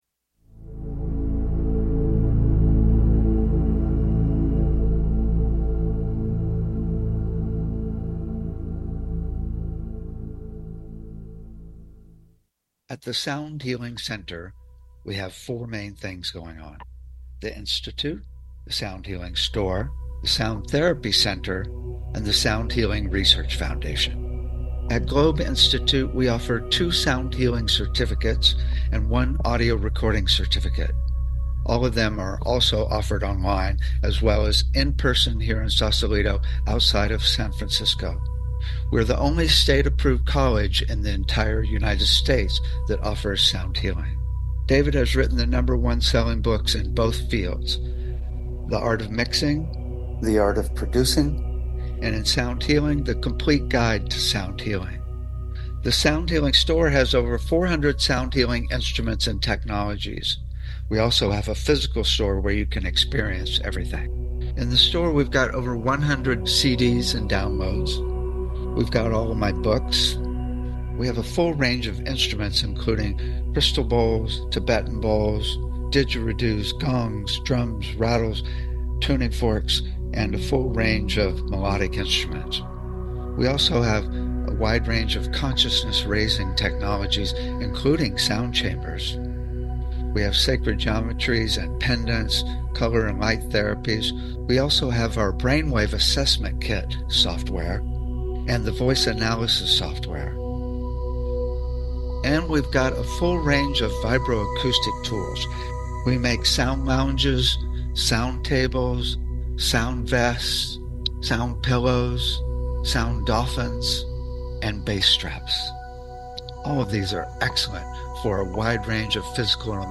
Talk Show Episode, Audio Podcast, Sound Healing and The Complete Guide To Sound Healing on , show guests , about The Complete Guide To Sound Healing, categorized as Education,Energy Healing,Sound Healing,Love & Relationships,Emotional Health and Freedom,Mental Health,Science,Self Help,Spiritual